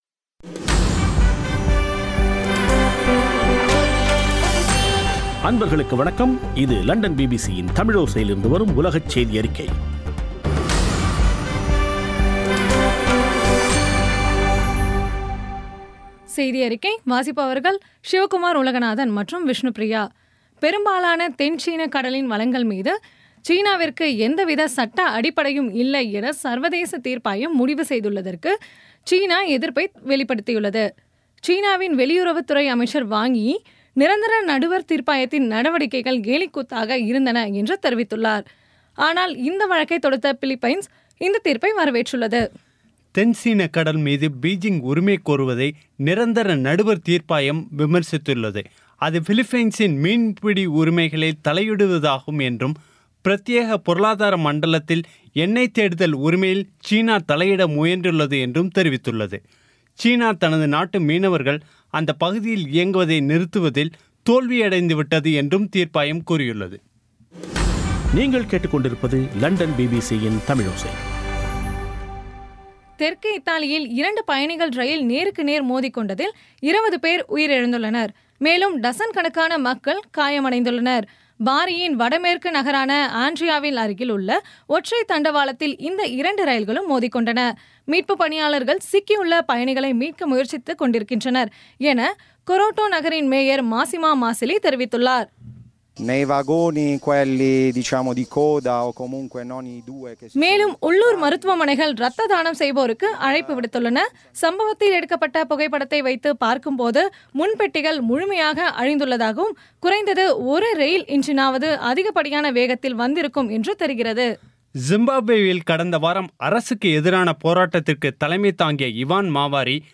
பிபிசி தமிழோசை செய்தியறிக்கை (12.07.2016)